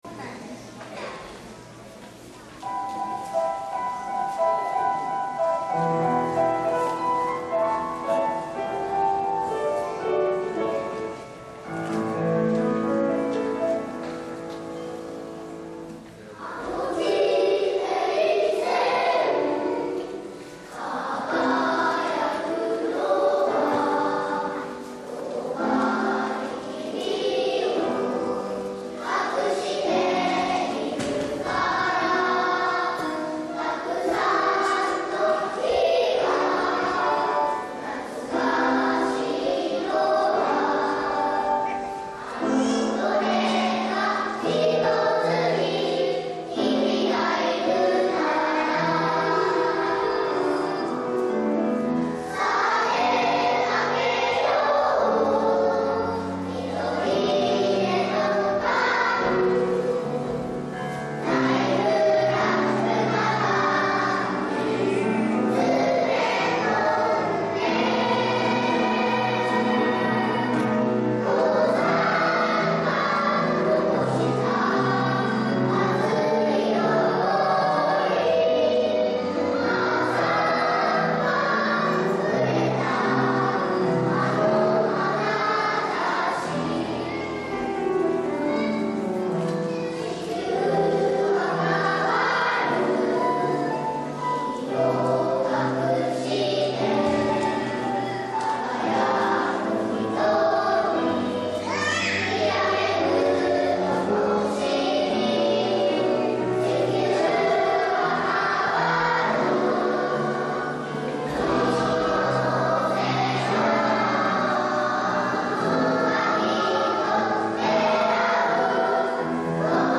開演前には、講堂が大空の子どもたち、地域・サポーター・ゲストのみなさんでいっぱいになりました。
低学年チームによる２部合唱「
２年生は難しいハーモニー部分を担当し、１年生も低い音を出すことにチャレンジ！
みんなの天使の声で、会場中をつつむことができました♪